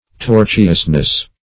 Tor"tu*ous*ness, n.